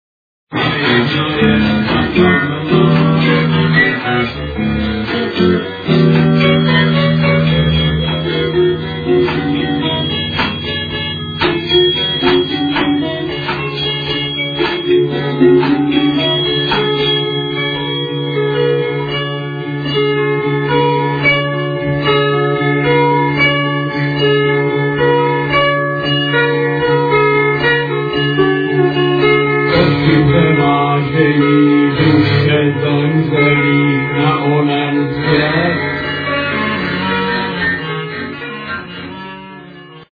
alternative group